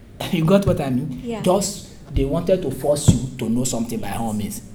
S1 = Bruneian female S3 = Nigerian male
The problem arises because of the presence of fricative noise, making is sound like [h], at the start of all .